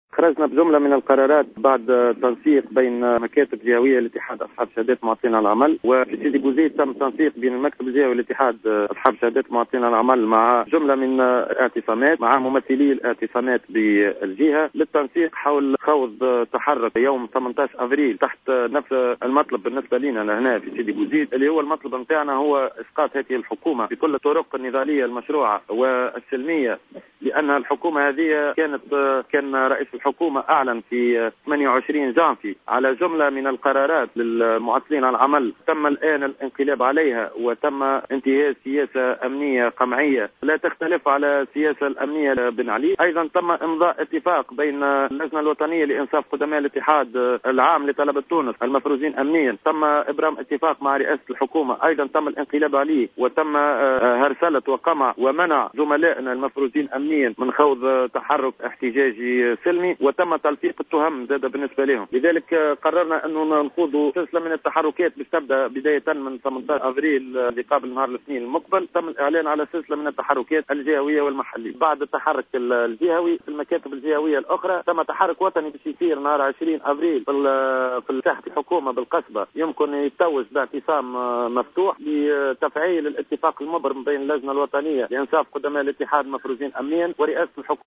في تصريح هاتفي للجوهرة أف أم